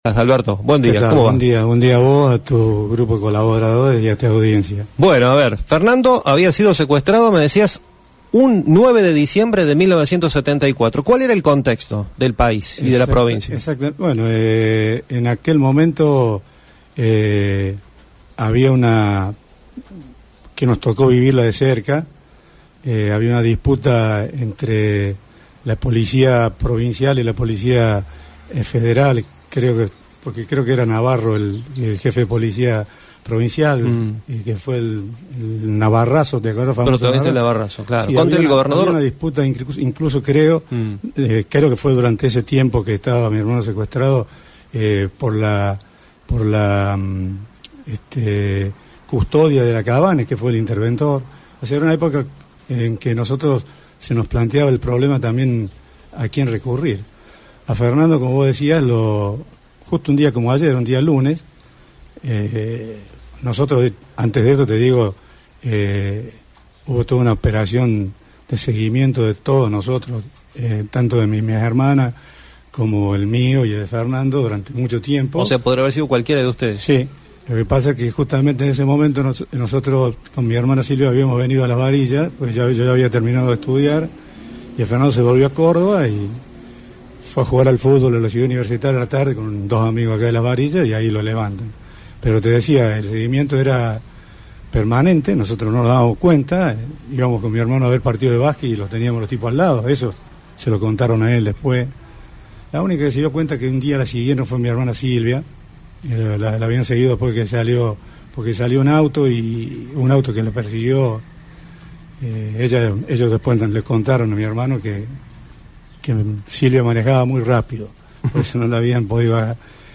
En diálogo con FM Identidad, recordó que ese secuestro se dio en 1974, época donde este tipo de acciones criminales era bastante común.